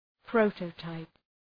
Προφορά
{‘prəʋtətaıp} (Ουσιαστικό) ● πρωτότυπο